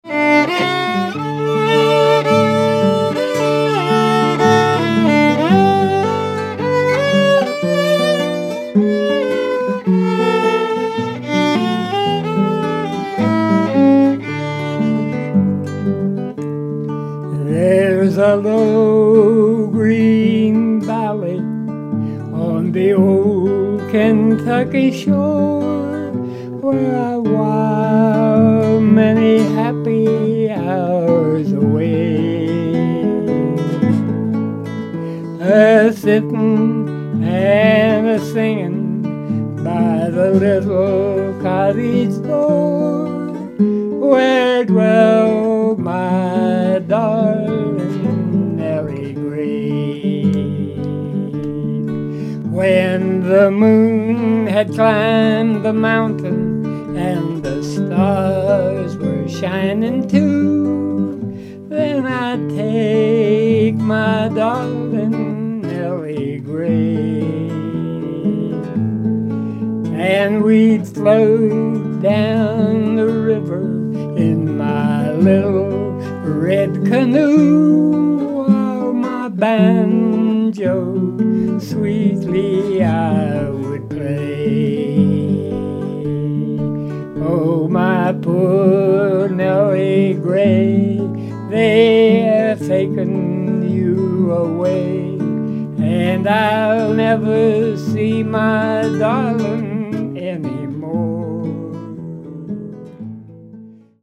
This two CD set of heritage music is a project of the Hampshire County, West Virginia 250th Anniversary Committee and is taken from over eighteen hours of archived music.